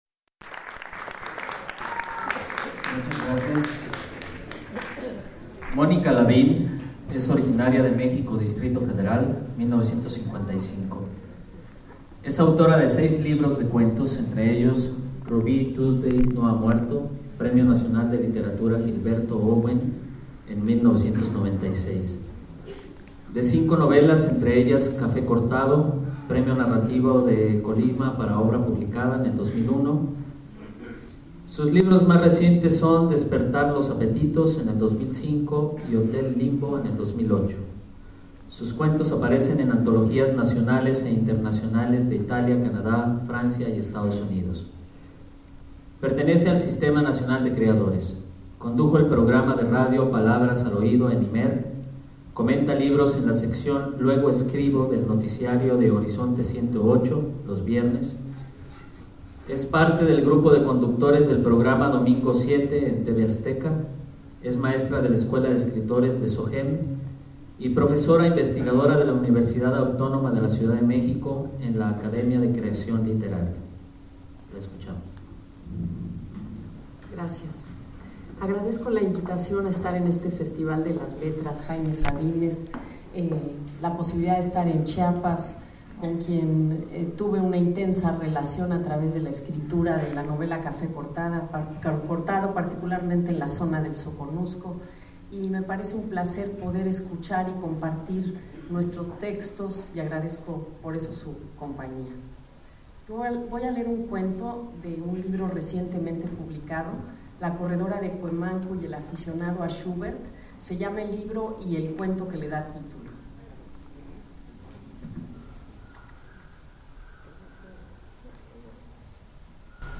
Lugar: Teatro Daniel Zebadúa de San Cristóbal de Las Casas, Chiapas. Mexico. Equipo: iPod 2Gb con iTalk Fecha: 2008-11-06 13:09:00 Regresar al índice principal | Acerca de Archivosonoro